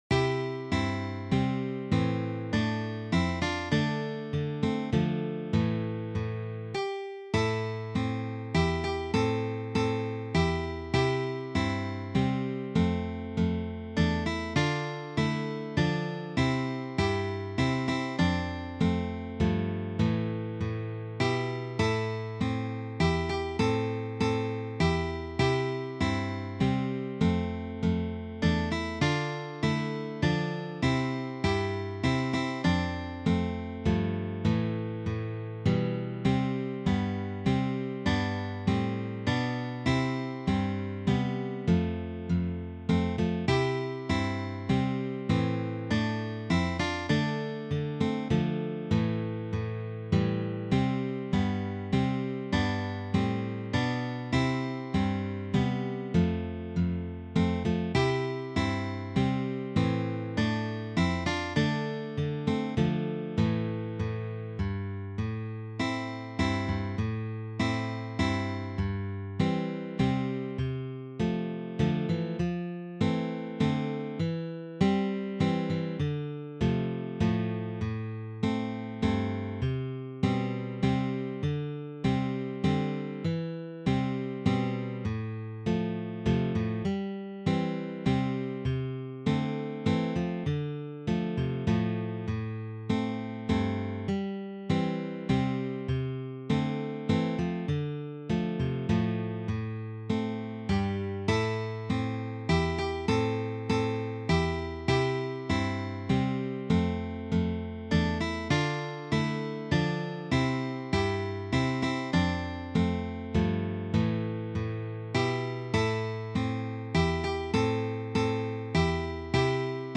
This folk selection is arranged for guitar trio.